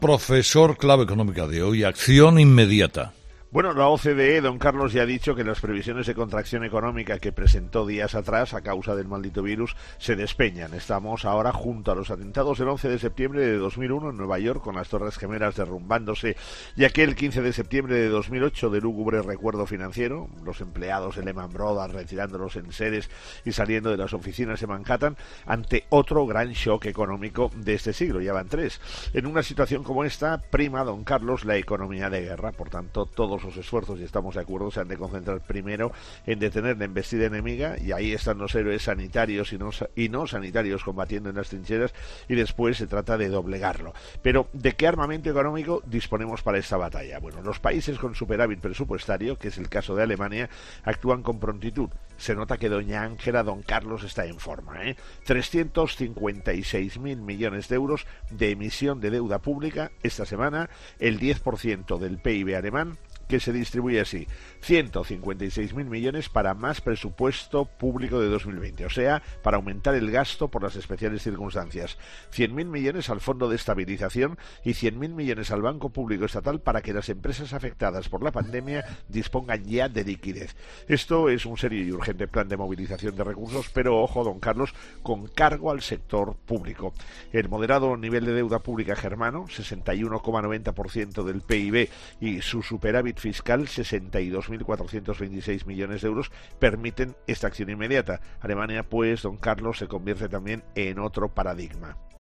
El profesor José María Gay de Liébana analiza en ‘Herrera en COPE’ las claves económicas del día.